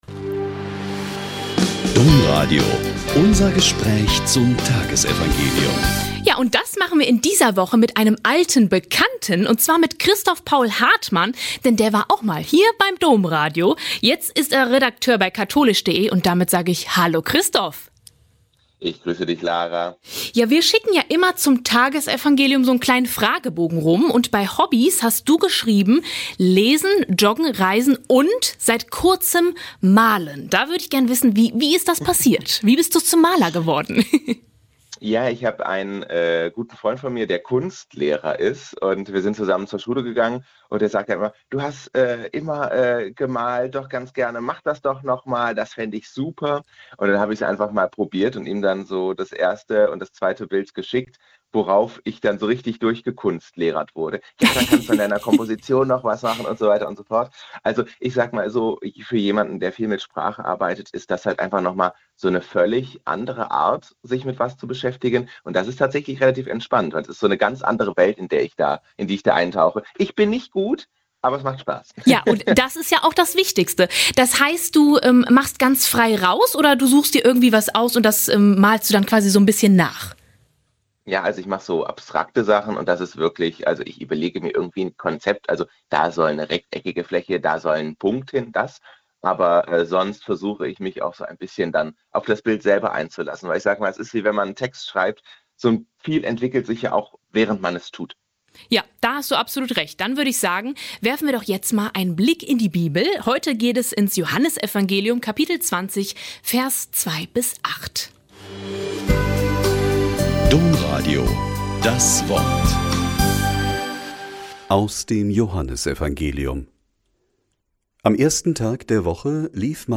Joh 20,2-8 - Gespräch